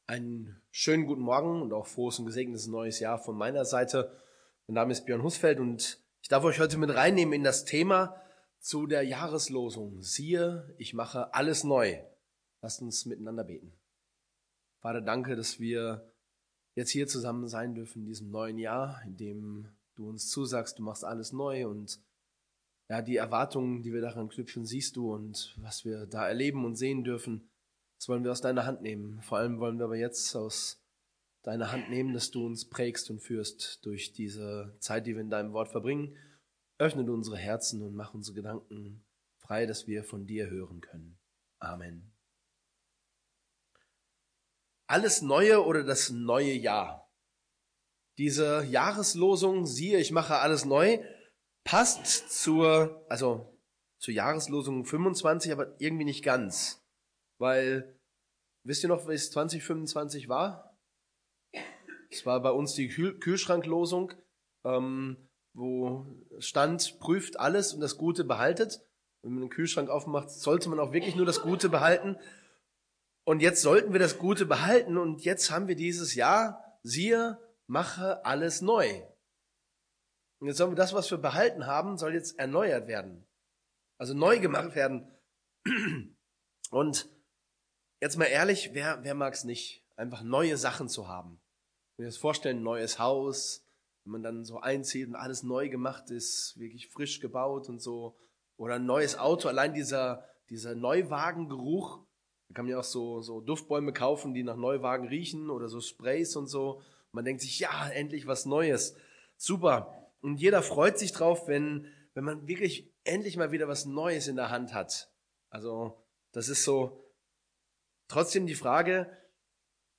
Jesus macht Himmel und Erde neu ~ Predigten aus der Fuggi Podcast